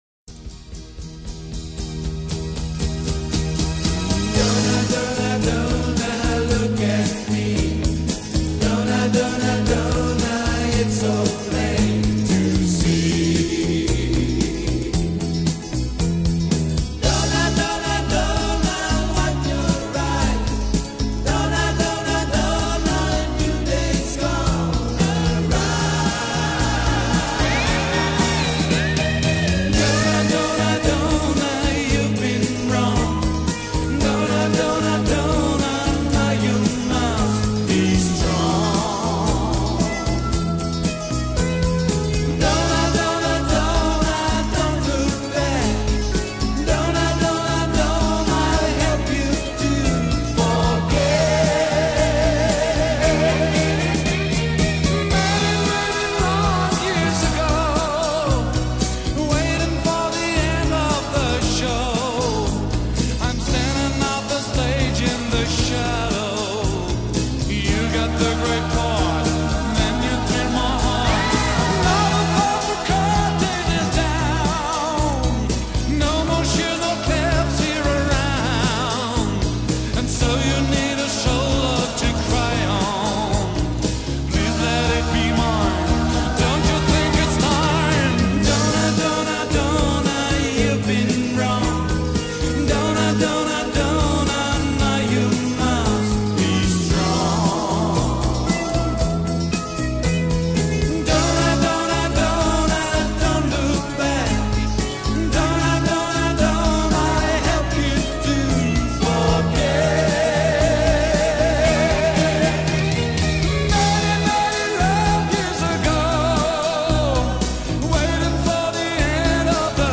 Рип с видео...